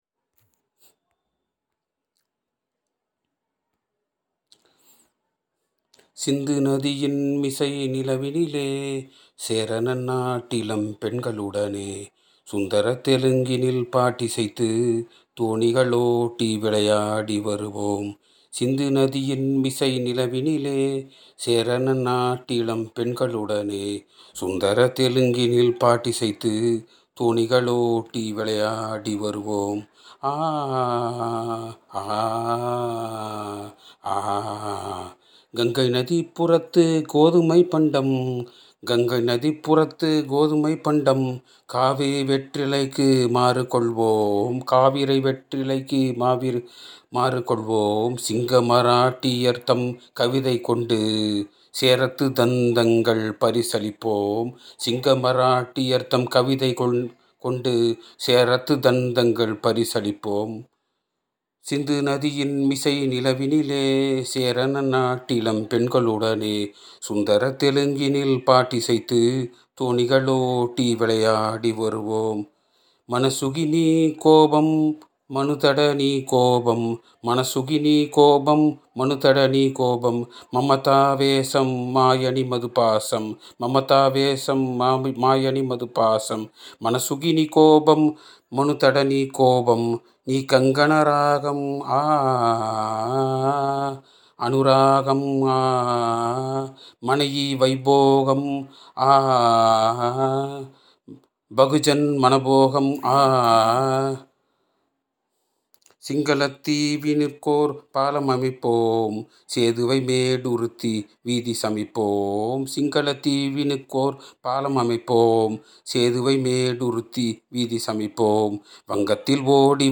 SINDHU NADHI – PATRIOTIC SONG